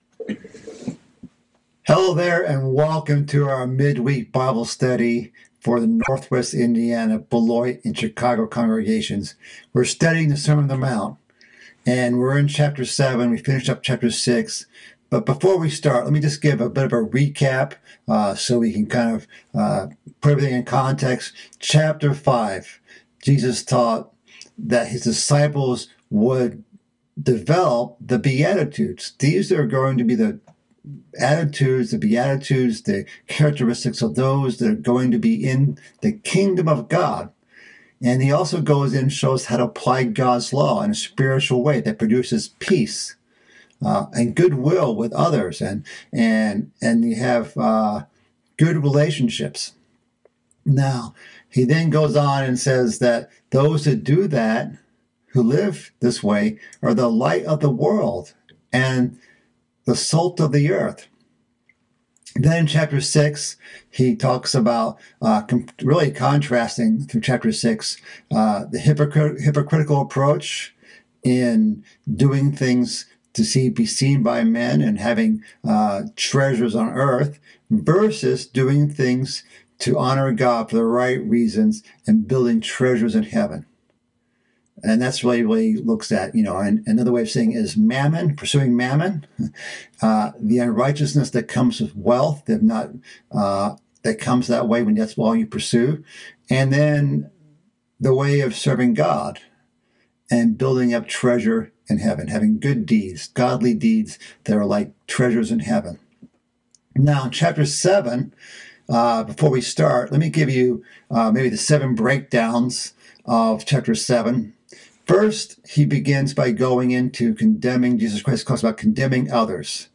This is part of a mid-week Bible study series about the sermon on the mount. This part begins chapter 7 and covers the topic of judgment, and removing the plank from your own eye.